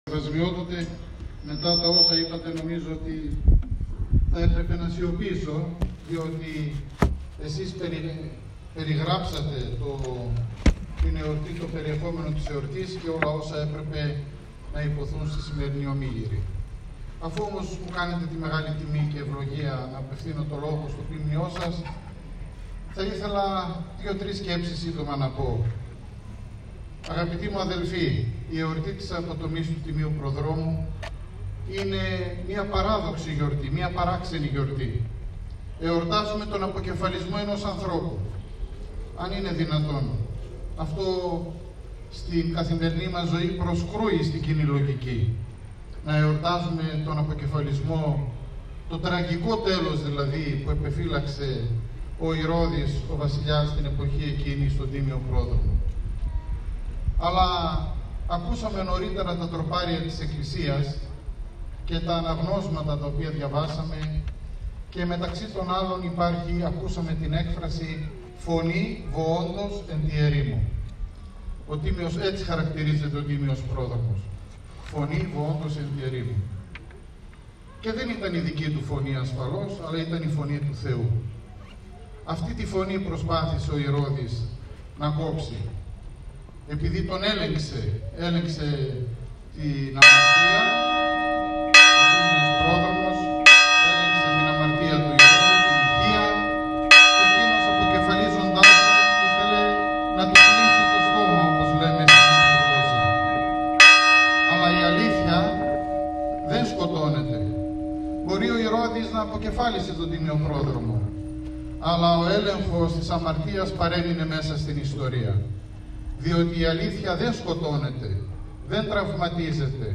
Μέλη των Πολιτιστικών Συλλόγων Μικρασιατών, Ποντίων, Σαρακατσάνων και του Λυκείου Ελληνίδων Ξάνθης μέ παραδοσιακές στολές εκατέρωθεν των Ιερών Εικόνων καθως και οι ιερεις , οι ιεροψάλτες μαζί με τις αρχες τόπου με λιτανευτικο τρόπο εξήλθαν του ιερού ναού και κατευθύνθηκαν στον προαύλιο χώρο του ναού στην πλατεία μητροπολεως όπου τελέσθηκε η Αρτοκλασία κατά την οποία ευλόγησε τούς Άρτους ο Σεβασμιώτατος Μητροπολίτης Κίτρους Κατερίνης και Πλαταμώνος κ. Γεώργιος ο οποιος κυρηξε και τον θείο λόγο.
Ακούστε εδώ την ομιλία του Σεβασμιωτάτου Μητροπολίτου Κίτρους κ. Γεωργίου: